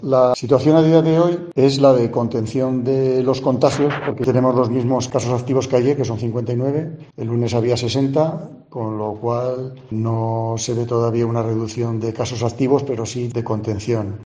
Carlos Paúl, alcalde de Rincón de Soto, describía así la situación de su municipio el 16 de septiembre